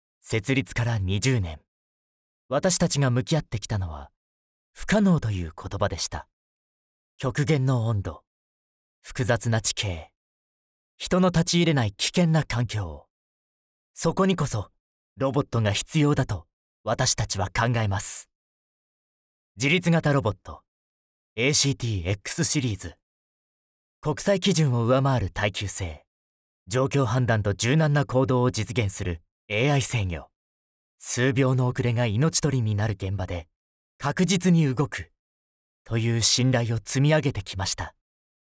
優しい声から厚みのある声まで幅広い声質で、感性豊かに、さまざまな場面に対応できます。
– ナレーション –
落ち着いた